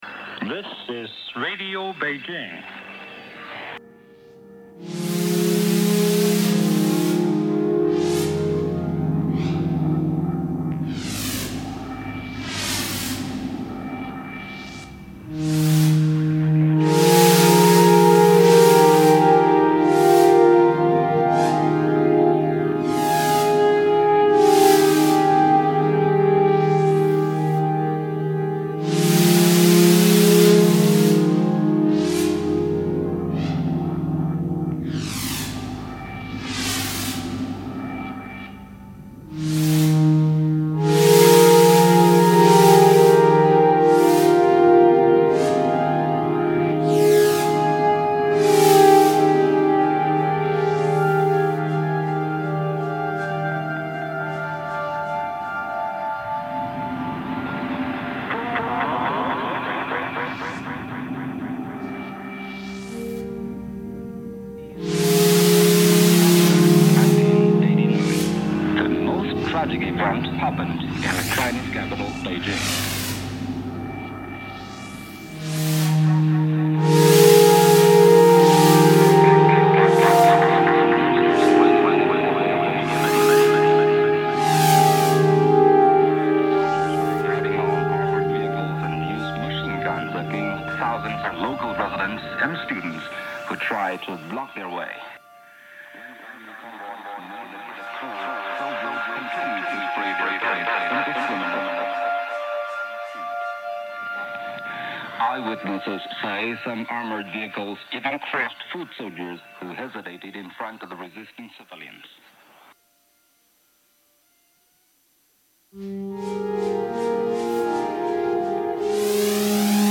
free form electronic drone